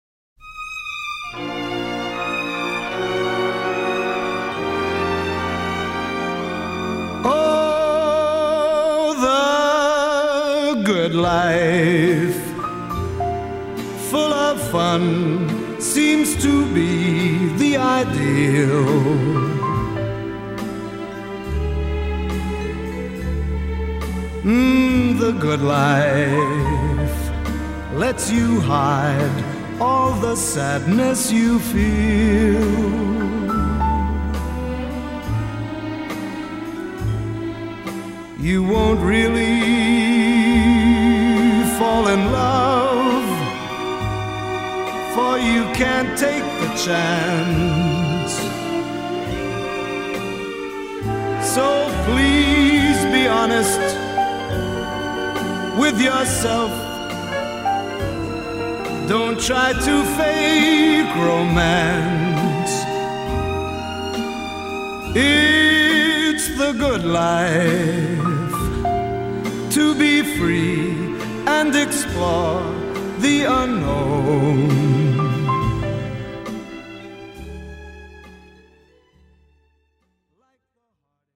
Жанр: R&B • Soul • Blues • Jazz